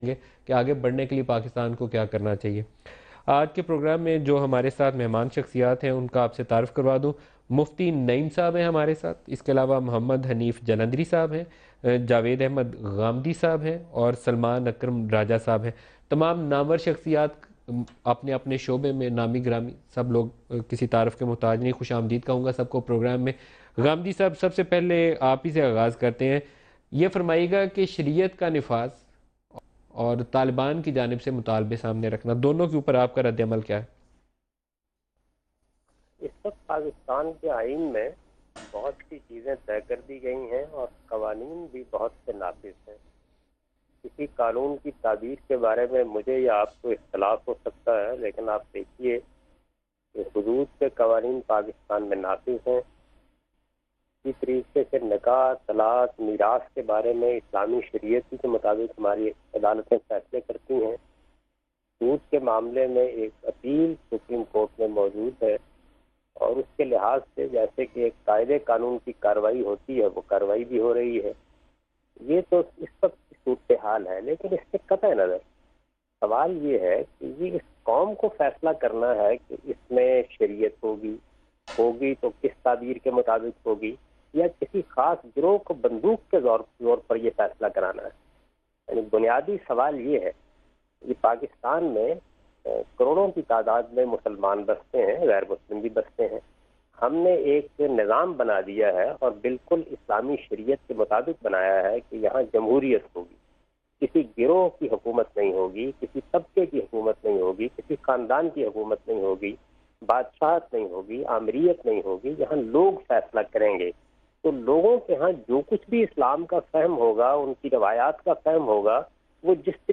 Category: TV Programs / Samaa Tv / Questions_Answers /
سماء نیوز کے پروگرام غامدی کے ساتھ میں جاوید احمد صاحب غامدی ”نفاذ شریعت اور طالبان کا مطالبہ“ سے متعلق سوالات کے جواب دے رہے ہیں